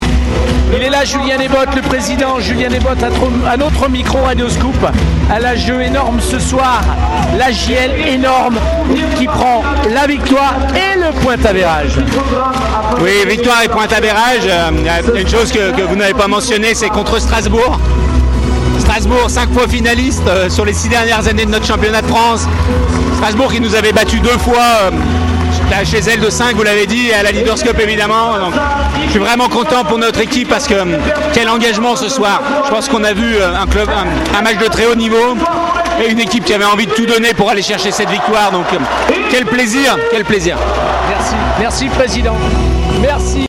Les interviews